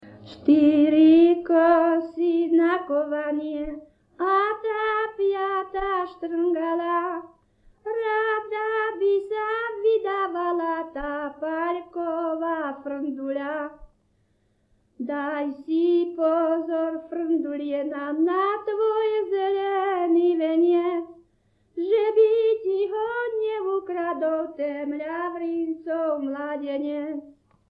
Popis sólo ženský spev bez hudobného sprievodu
Miesto záznamu Litava
Kľúčové slová ľudová pieseň